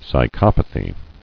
[psy·chop·a·thy]